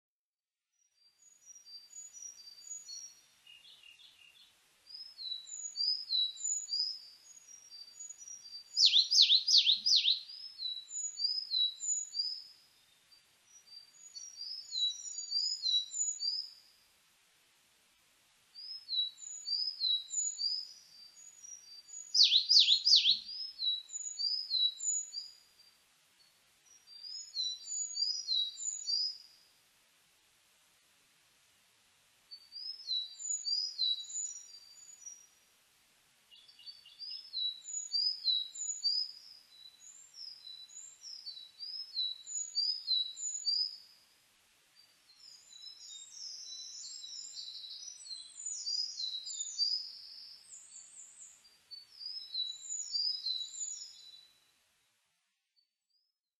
エゾムシクイ　Phylloscopus borealoidesウグイス科
日光市稲荷川上流　alt=1160m  HiFi --------------
Windows Media Audio FILE MPEG Audio Layer3 FILE  Rec.: MARANTZ PMD670
Mic.: audio-technica AT825
他の自然音：　 メボソムシクイ・コルリ・ミソサザイ